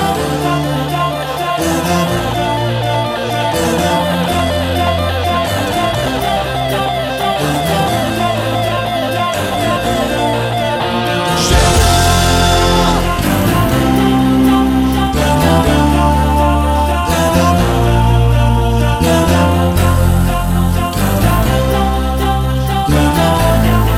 Two Semitones Down Soundtracks 3:49 Buy £1.50